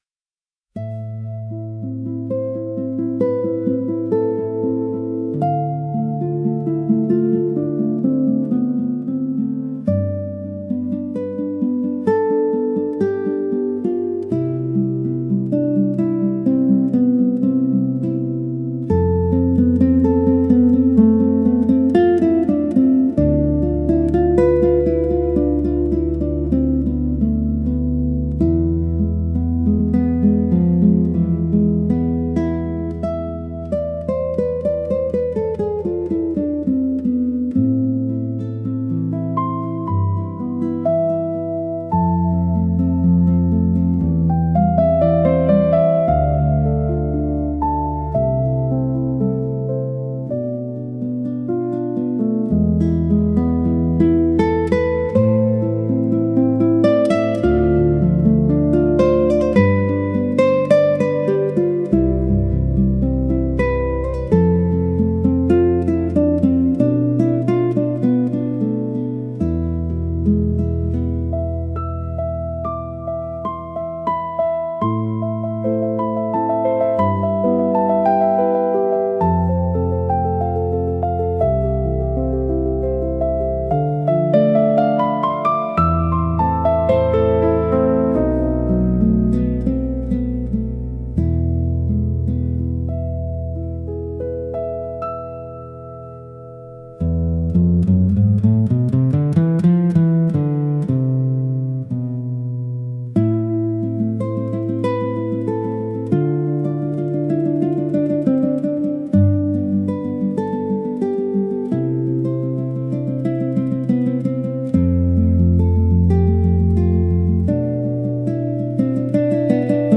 I thought you might also be interested in these versions generated from the prompt “music in the style of Bach’s Aria from the Goldberg Variations primarily piano with soft classical guitar accompaniment”: